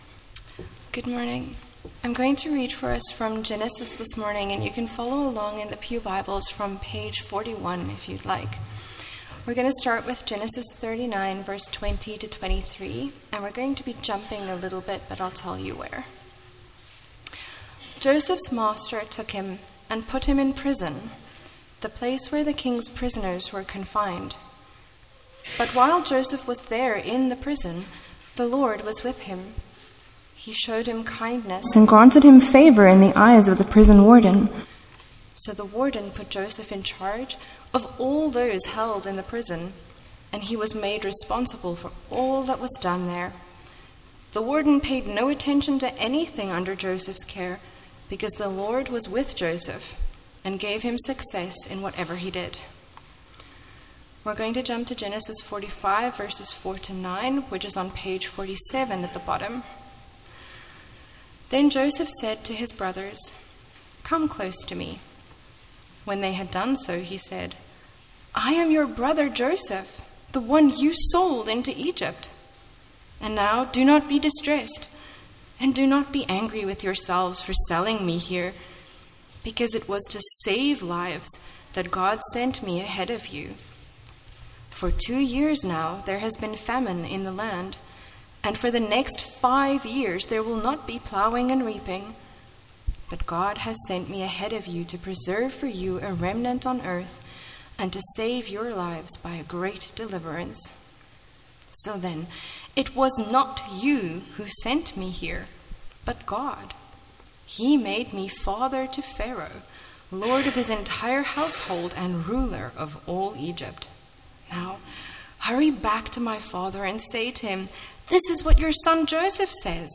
MP3 File Size: 26.4 MB Listen to Sermon: Download/Play Sermon MP3